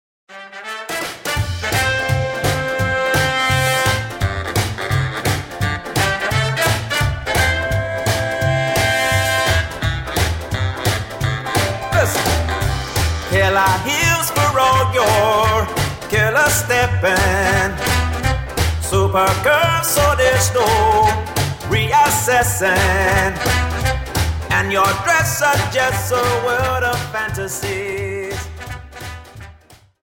Dance: Jive 42